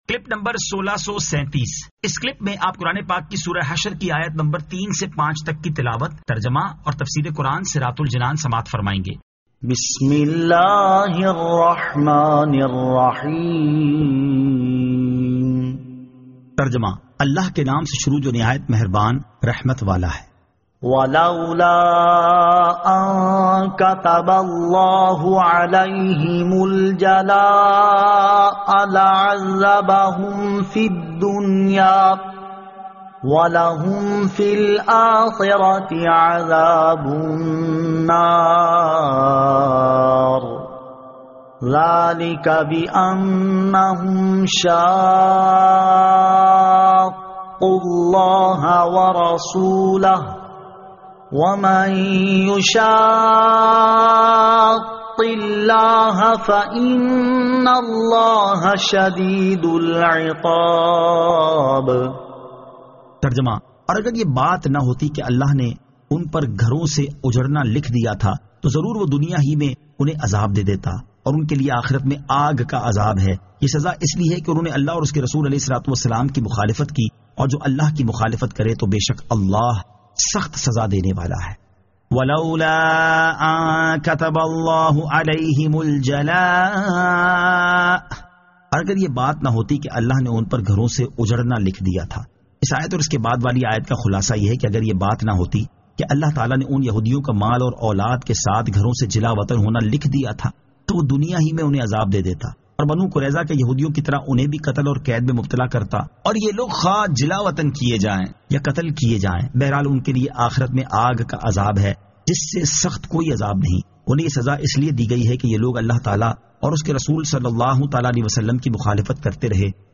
Surah Al-Hashr 03 To 05 Tilawat , Tarjama , Tafseer